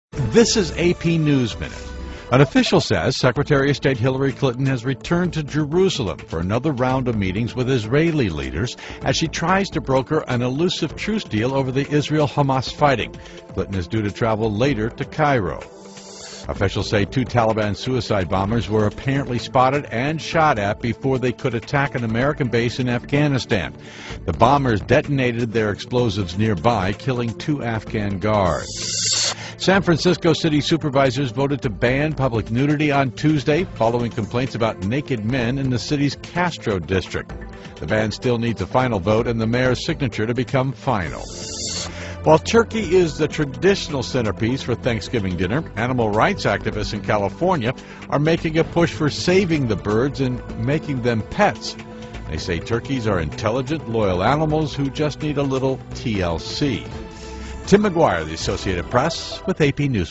在线英语听力室美联社新闻一分钟 AP 2012-11-24的听力文件下载,美联社新闻一分钟2012,英语听力,英语新闻,英语MP3 由美联社编辑的一分钟国际电视新闻，报道每天发生的重大国际事件。电视新闻片长一分钟，一般包括五个小段，简明扼要，语言规范，便于大家快速了解世界大事。